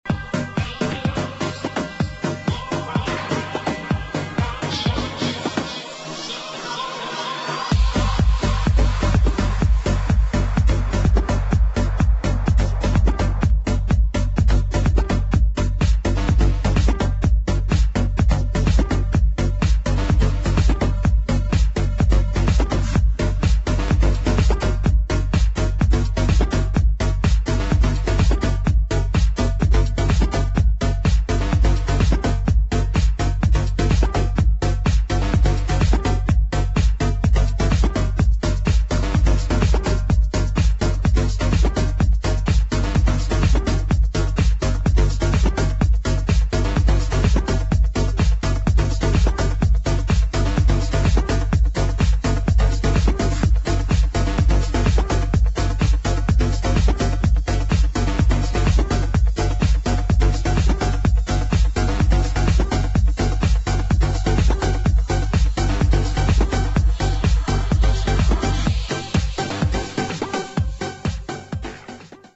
[ HOUSE / ELECTRO ]